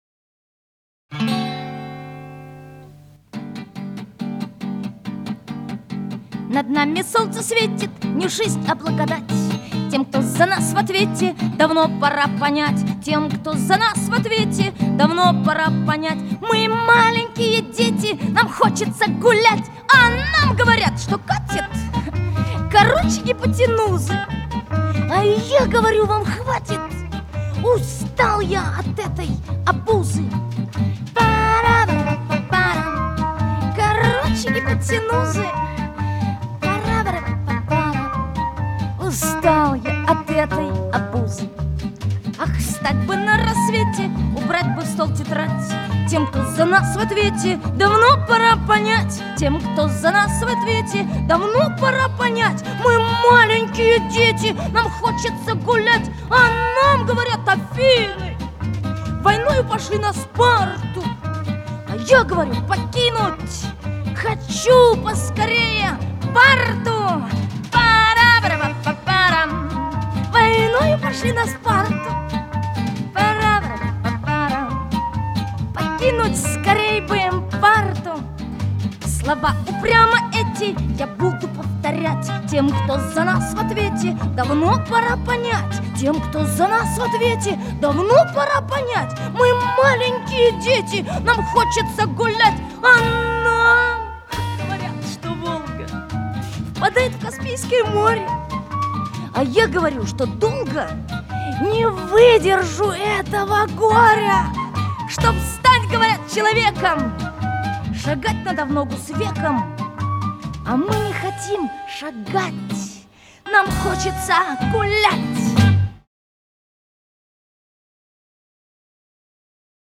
• Категория:Песни для малышей